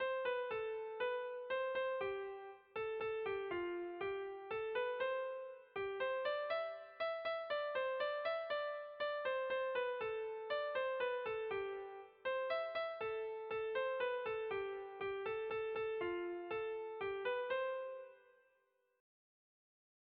Kopla handia
A-B-C-D